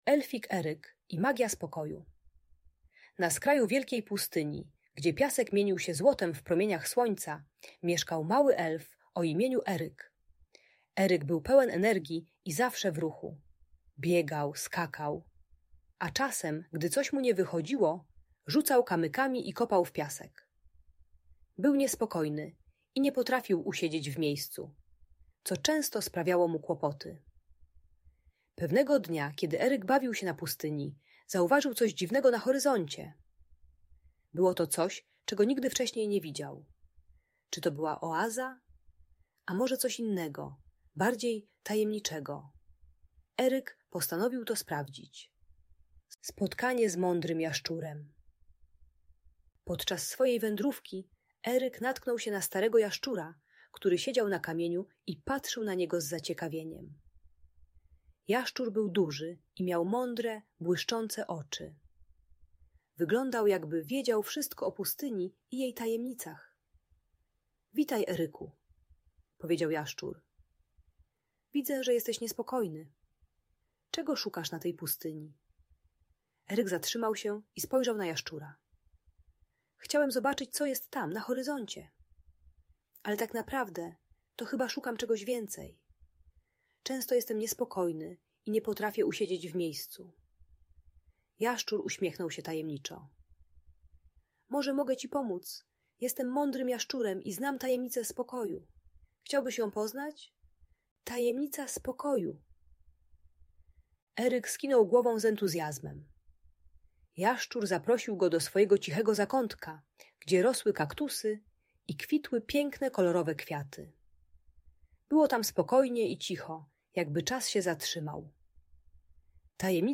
Elfik Eryk i Magia Spokoju - Bunt i wybuchy złości | Audiobajka